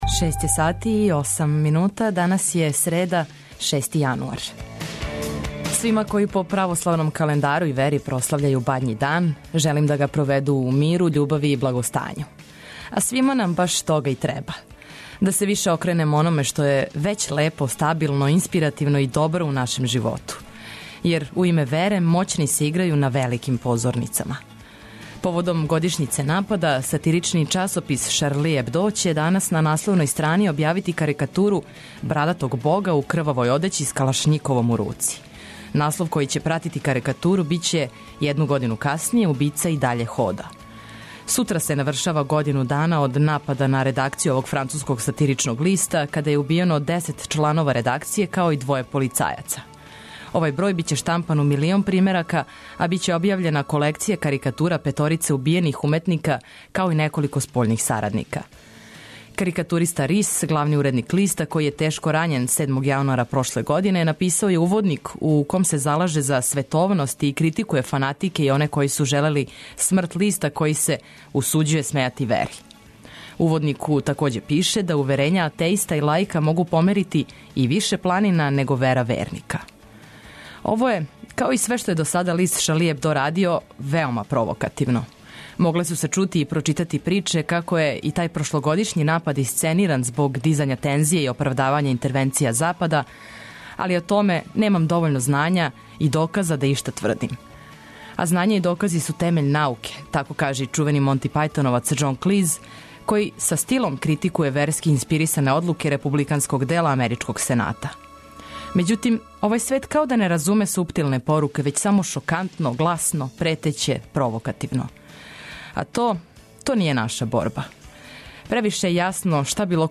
Празнично јутро биће лагано, опуштено, весело и уз много окрепљујуће музике и ћаскања са вама.